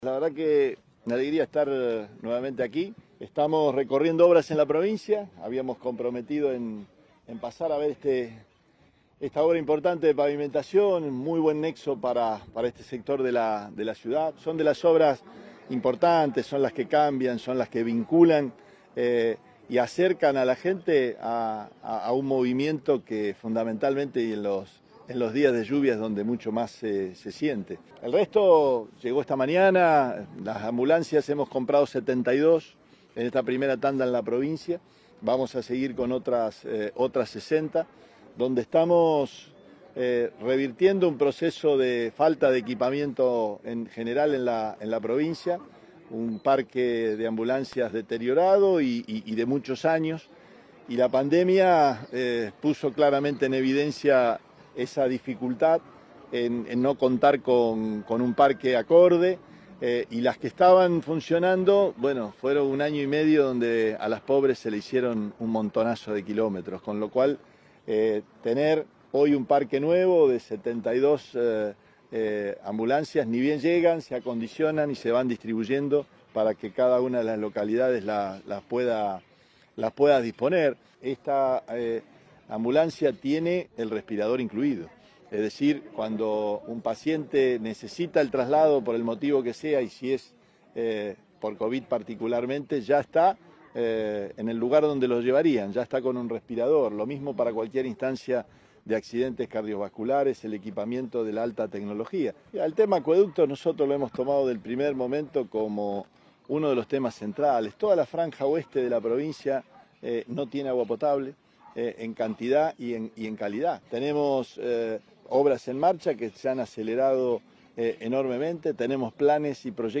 El gobernador durante la recorrida por la localidad de El Trébol